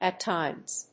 アクセントat tímes